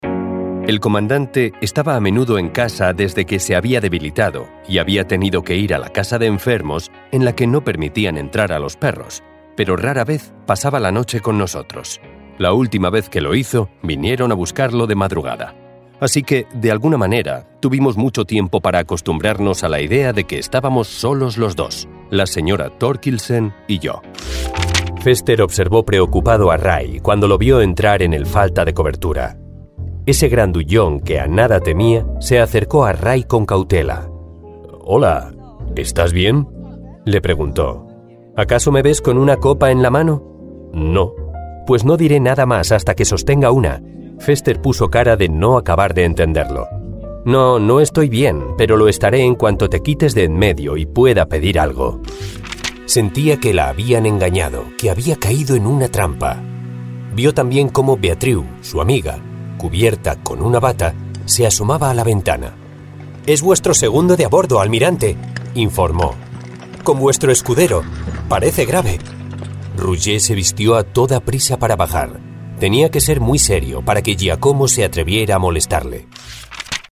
Audiobooks
Passionate and dynamic voice with a mid-range tone and a “guy next door” spark.
Offering vocal techniques that are flexible and colorful.
STUDIOBRICKS ONE PLUS SOUND BOOTH
NEUMANN TLM 103